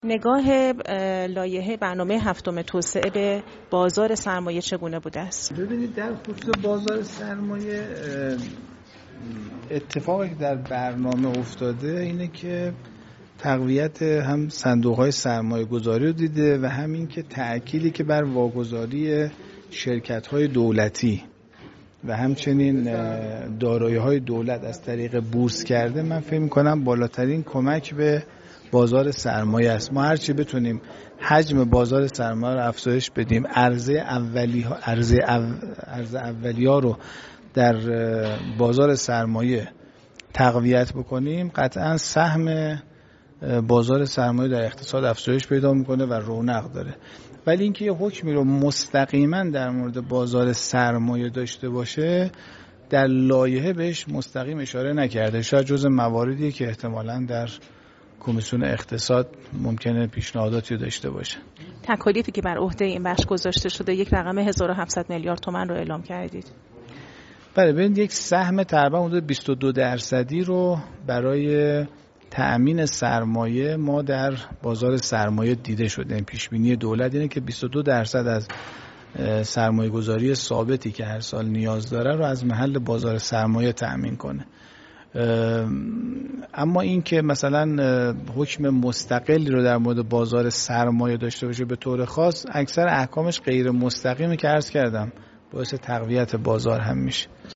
فردای اقتصاد: محسن زنگنه سخنگوی کمیسیون تلفیق برنامه هفتم در گفتگو با فردای‌اقتصاد درباره تکالیف بازارسرمایه گفت: دولت پیش‌بینی کرده که در طول سال‌های  برنامه هفتم، سالانه ۲۲درصد از سرمایه‌گذاری ثابت را از محل بازار سرمایه تامین کند.